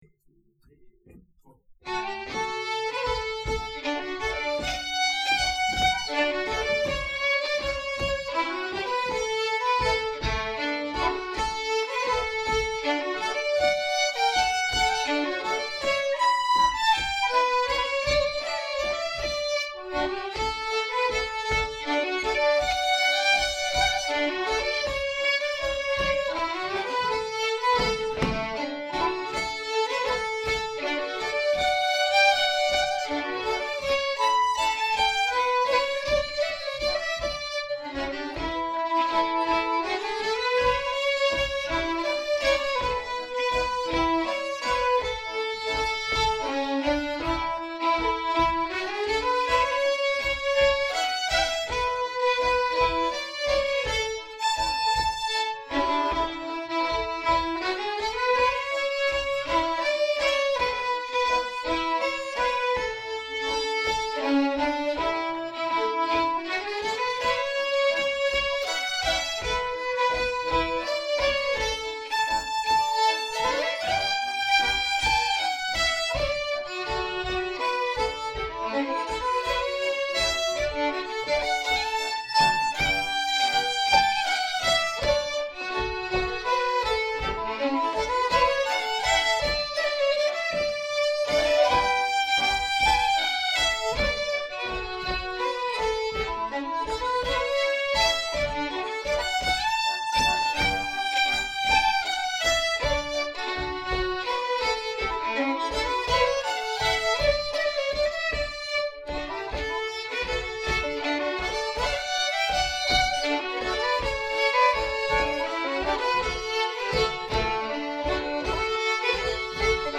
Med bland annat säckpipa, fiol bouzouki och cello, skapade gruppen ett gruppsound med bordunmusiken och den svenska säckpipan i centrum.
I Blå Bergens Borduner används även andra instrument såsom Hardingfela, Moraharpa, Vevlira, Stråkharpa, Cittern, Gitarr och olika blåsinstrument. Musiken kommer huvudsakligen från den lokala spelmanstraditionen i Närke, Värmland och Bergslagen plus en del eget material.
Vals-efter-Carl-Viktor-Rulin-_Annandagsgloggen_-3-2.mp3